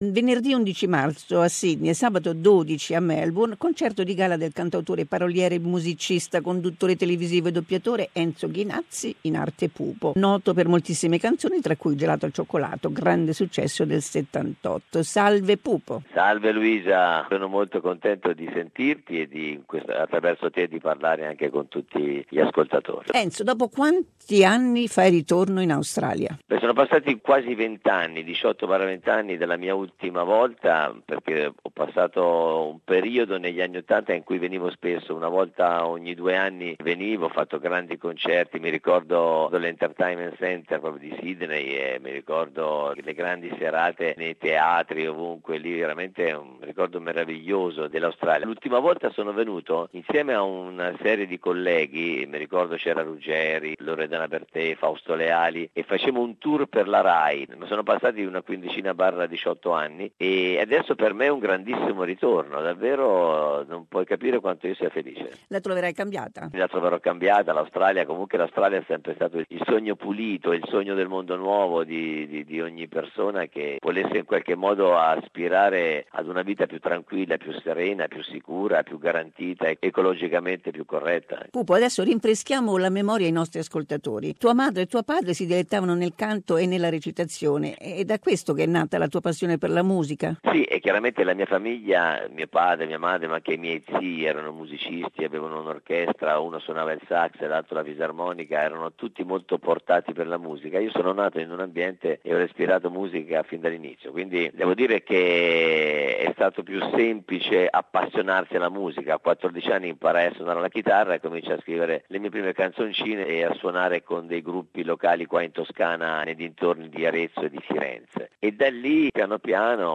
Italian pop-star Pupo returns to Australia for concerts in Sydney and Melbourne. In this interview the famous singer talks about his songs, books and the fight to defeat his gambling addiction.